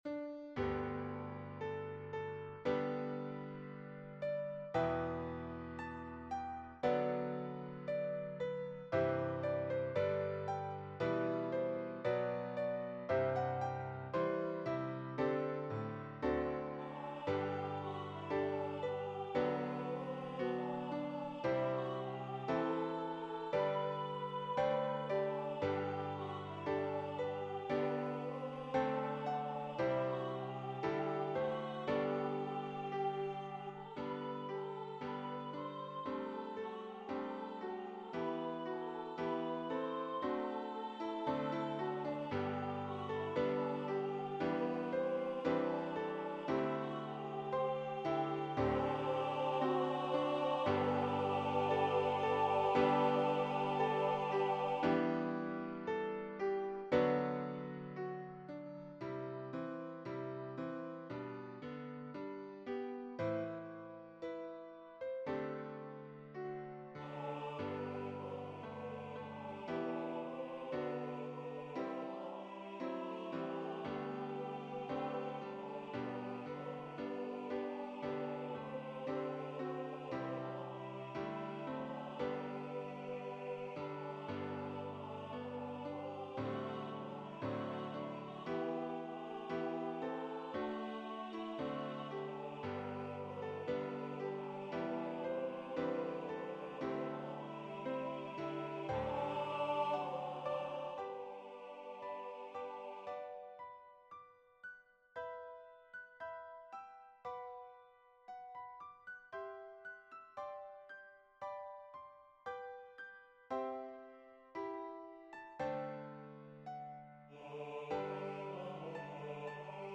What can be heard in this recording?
SATB, SSATB, SSATTB